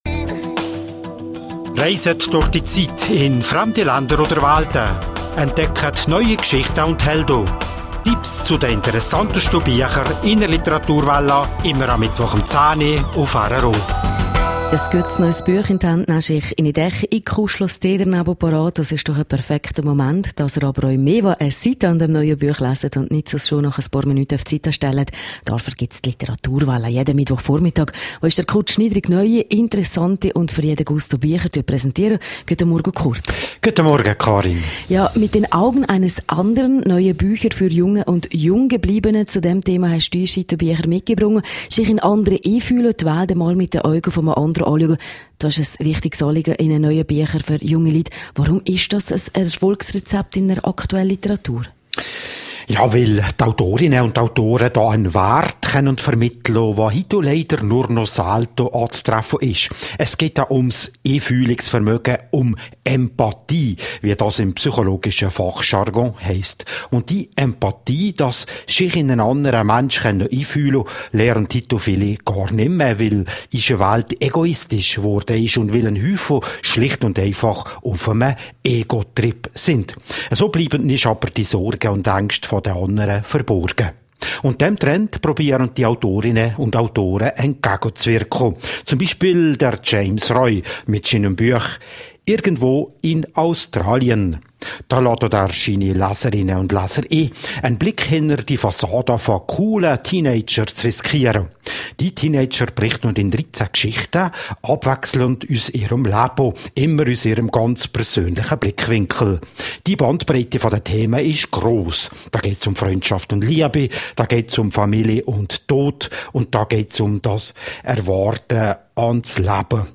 Radio Rottu Oberwallis